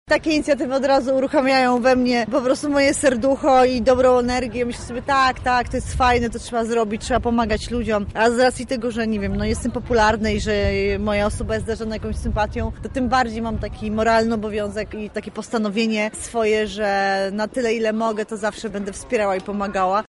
Wśród nich jest zaangażowana społecznie aktorka – Anita Sokołowska: